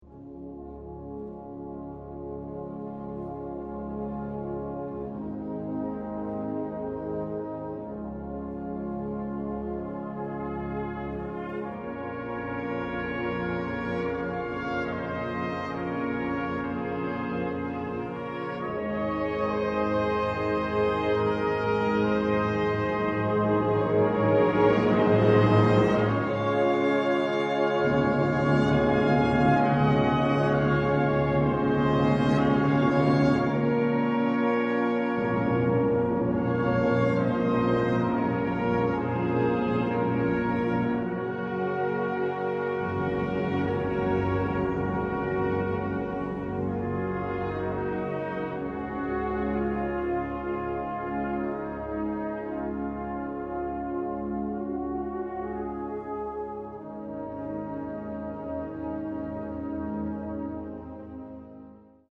Een mooi koraal/rustig werkje voor HaFa